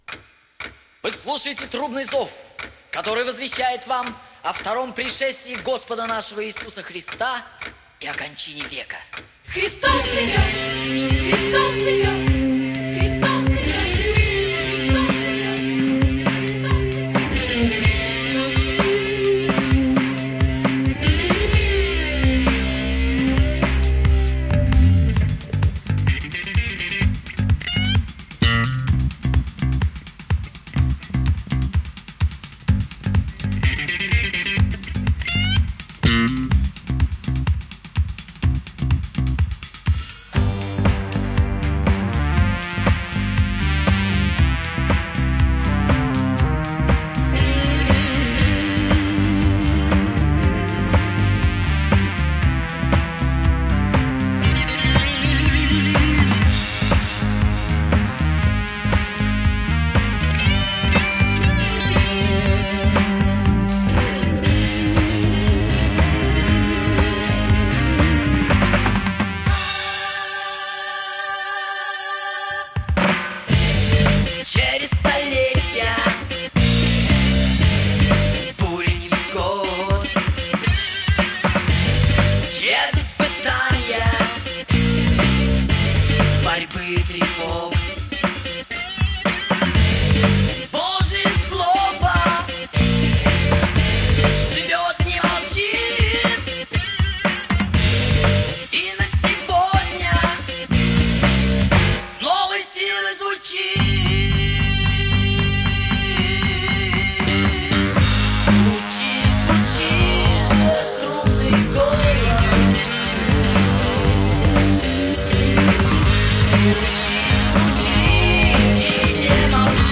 заново спеты партии женских голосов
Также произведён ремастеринг альбома.